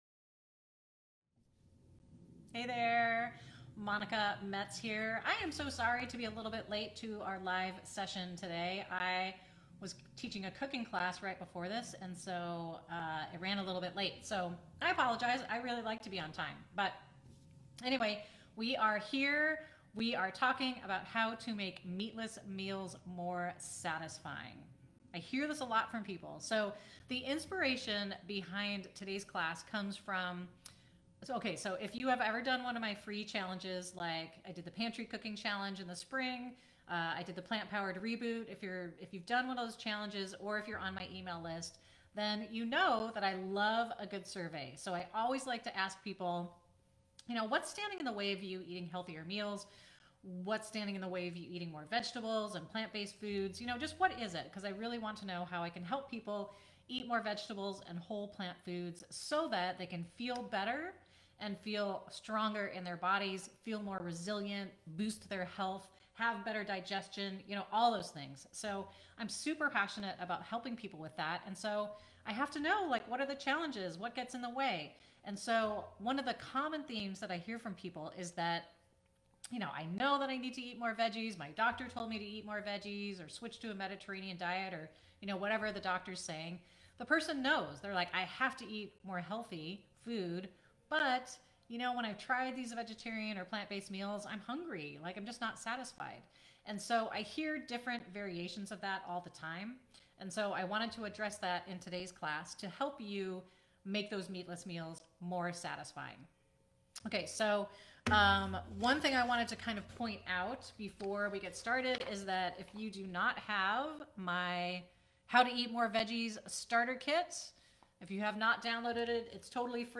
What's inside the How to Make Meatless Meals More Satisfying class recording